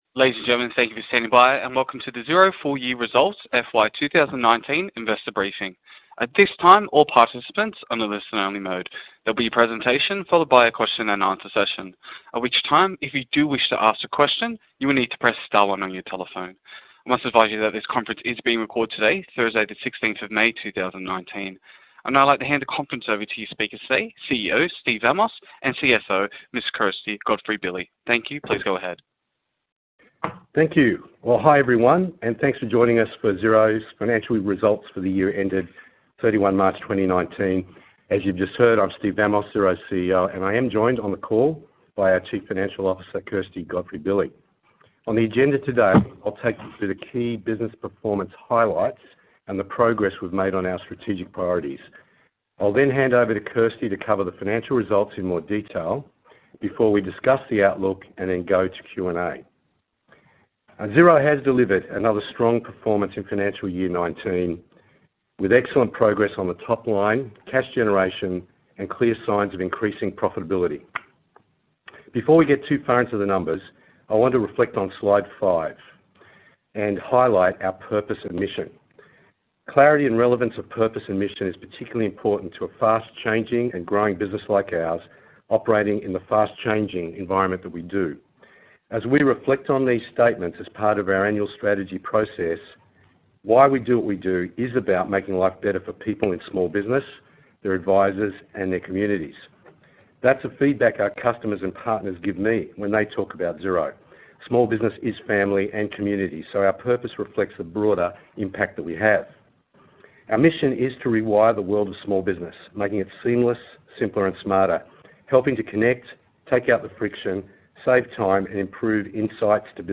Investor Briefing FY19 (MP3)